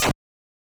snd_break1.wav